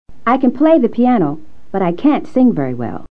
Como regla general, la pronunciación de CAN'T nunca se reduce mientras que la de CAN suele reducirse en oraciones completas pero no en respuestas cortas.
Observa el texto e intenta repetirlo después del profesor.